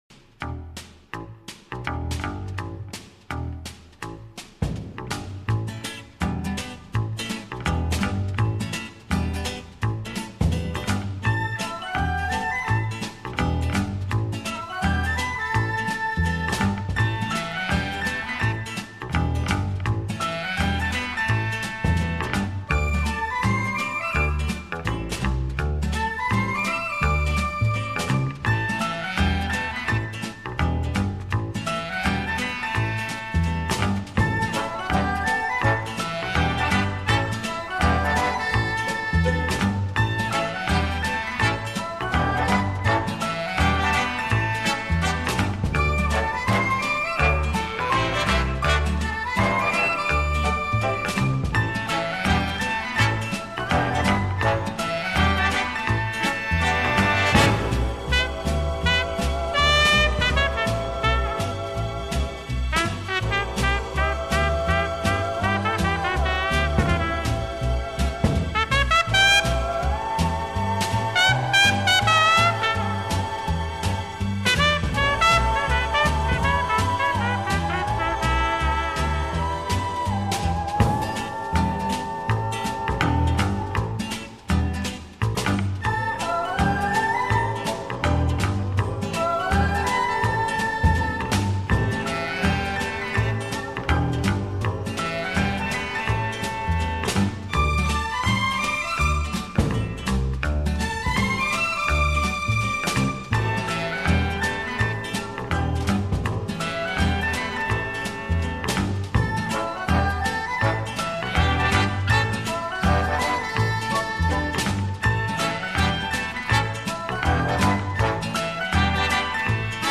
Genre:Jazz
Style:Easy Listening